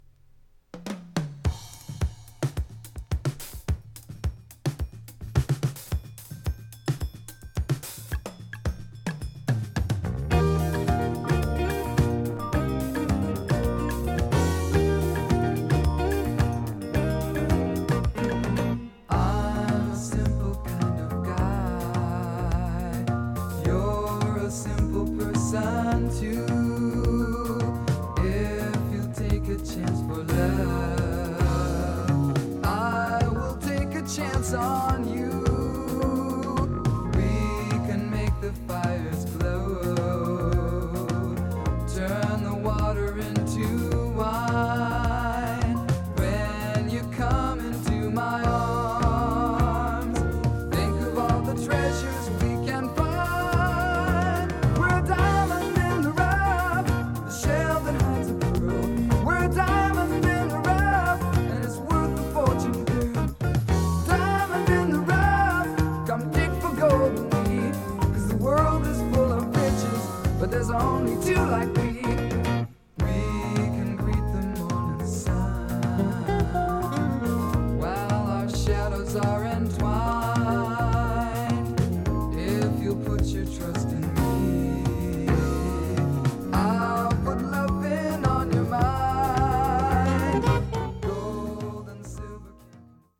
軽妙かつ洗練された所謂コンテンポラリージャズ作品です。